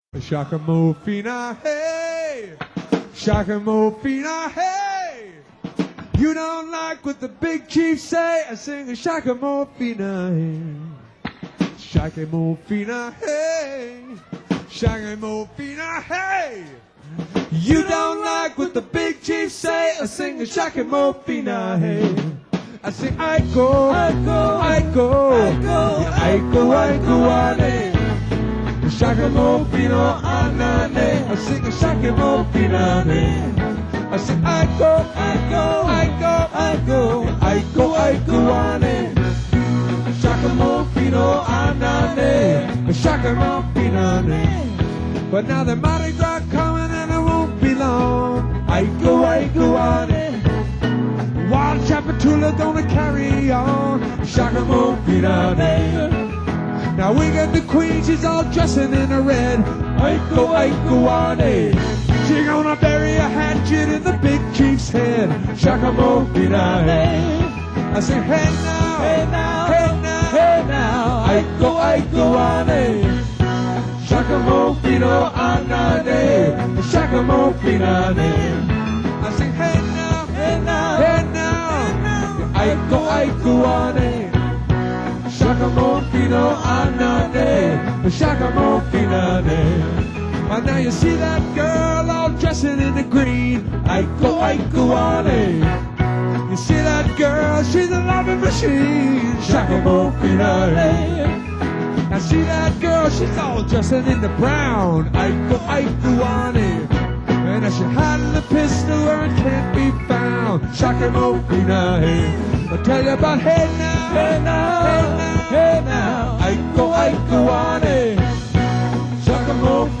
keyboards & vocals
drums
bass & vocals
guitar & vocals